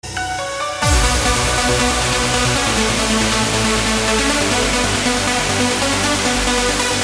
• Качество: 320, Stereo
громкие
без слов